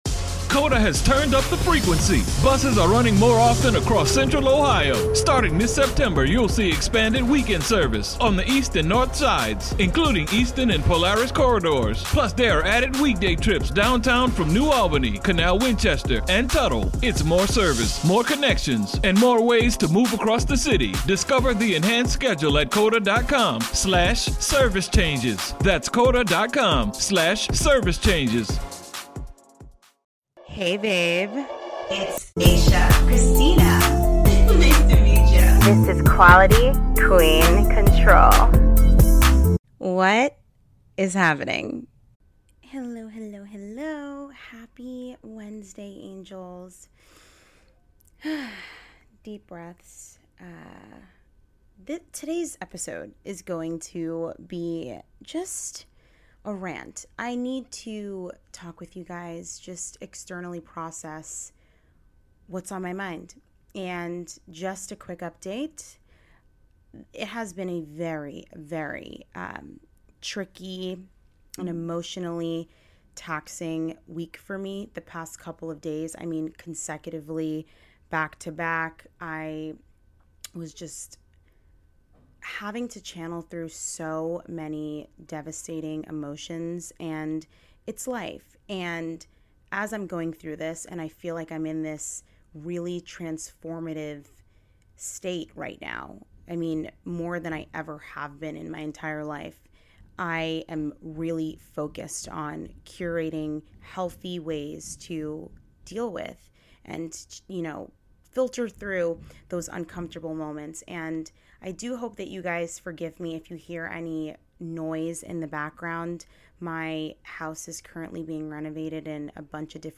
Progress Over Perfection Rant
Hi Angels, and today’s episode, I go on a long rant, externally processing why we should strive for progress and not perfection.